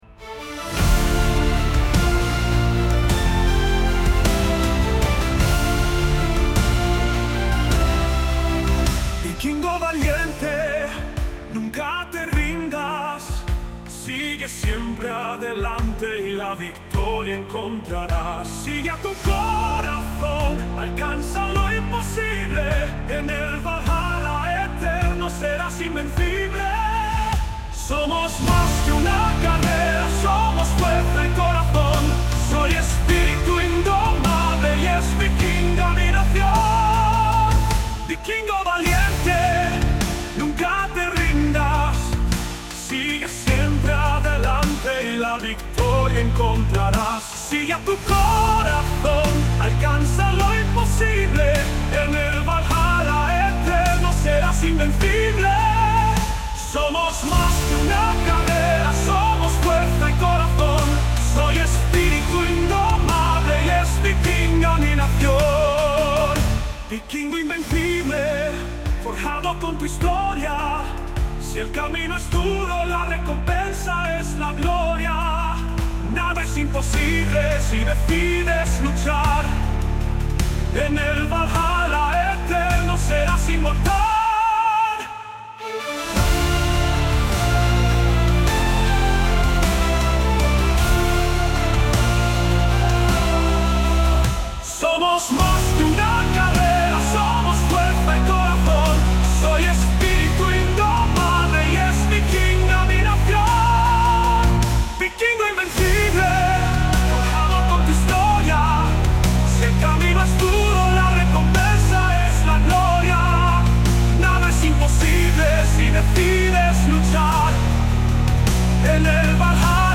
Epic, Lento